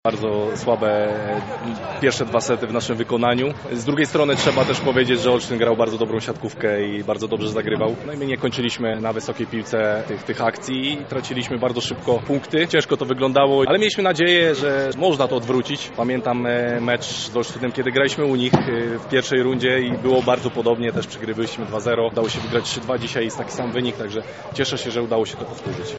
Wywiady pomeczowe